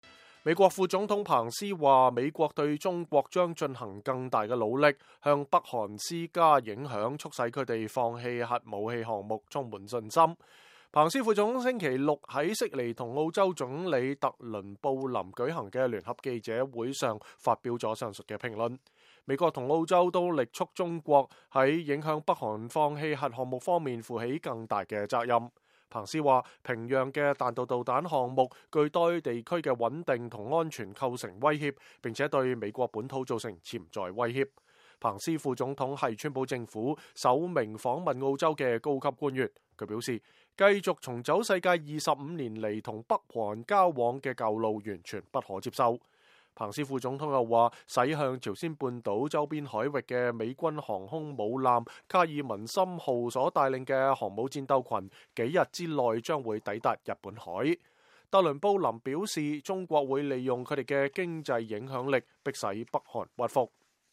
美國副總統彭斯表示，美國對中國將進行更大努力，向北韓施加影響，促其放棄核武器項目充滿信心。彭斯副總統星期六在悉尼與澳洲總理特倫布林舉行的聯合記者會上說了這番話。